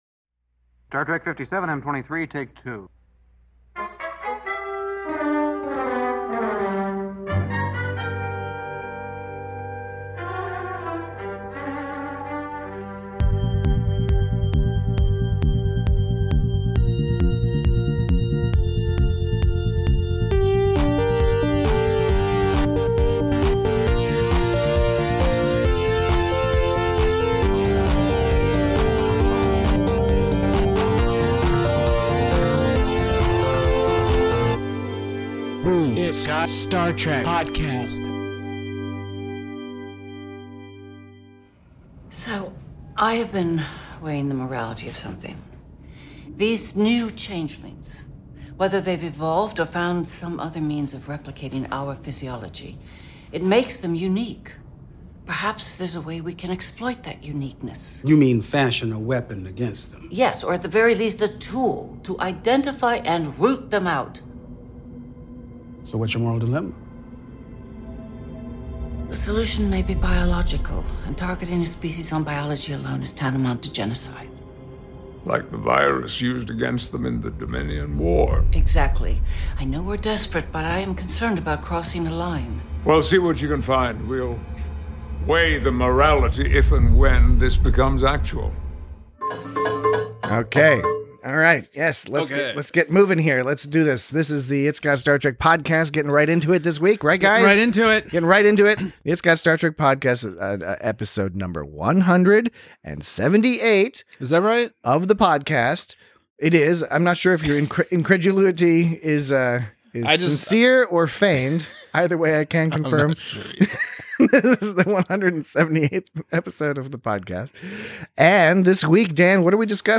Admiral Picard uses his son as bait in a wicked game of cat and mouse. Join your predominantly anti-genocide hosts as they discuss sinister backstories, multiple mysteries, and activities that should be generally avoided on the elevator.